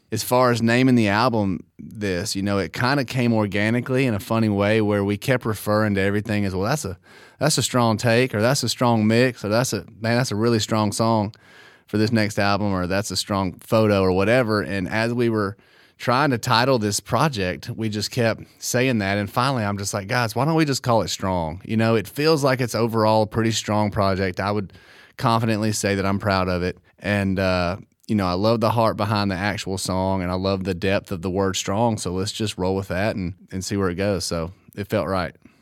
Tyler Hubbard talks about titling his new album, Strong.